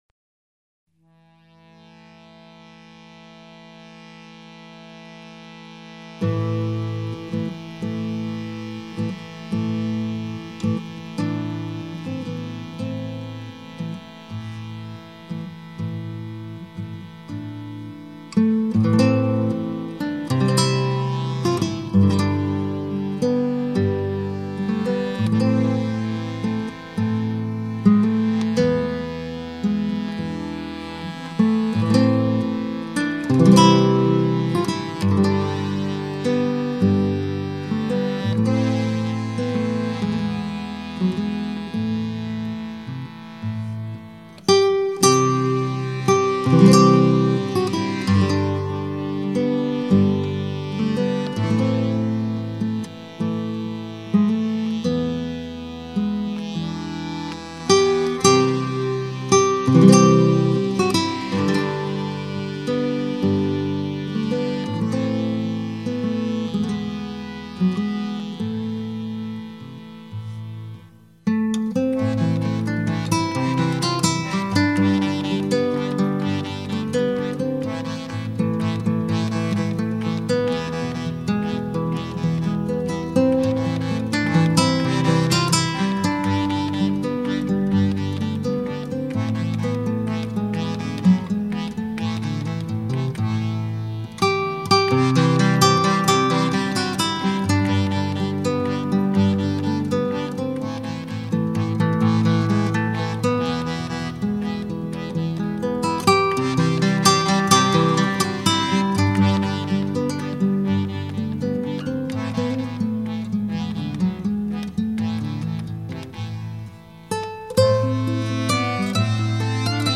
Гитарные пьесы
Саунд светлый и радостный, музыка лёгкая и доступная.
Английская народная песня